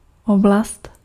Ääntäminen
Synonyymit orbe Ääntäminen France: IPA: [ɔʁ.bit] Haettu sana löytyi näillä lähdekielillä: ranska Käännös Ääninäyte Substantiivit 1. oblast {f} Muut/tuntemattomat 2. oběžná dráha {f} 3. oční důlek {m} Suku: f .